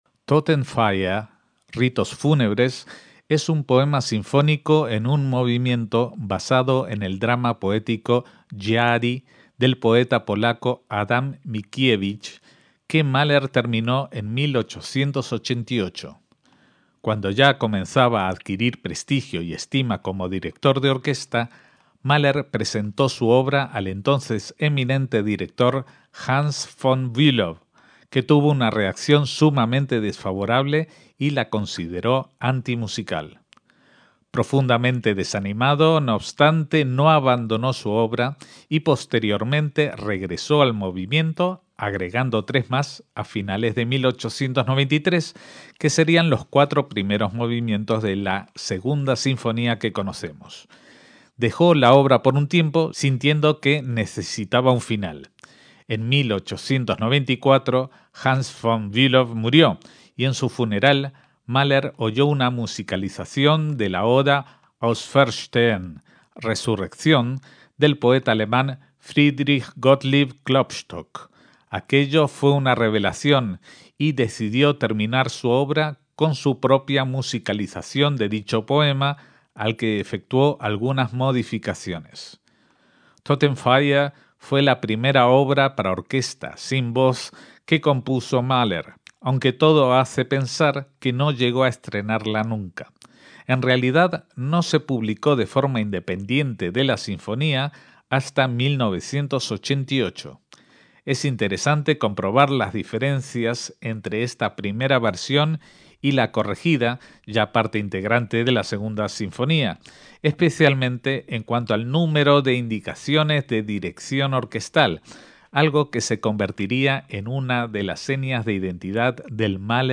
poema sinfónico de único movimiento